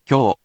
We’re going to show you the character(s), then you you can click the play button to hear QUIZBO™ sound it out for you.
In romaji, 「きょ」 is transliterated as 「kyo」which sounds sort of like「kyoh」.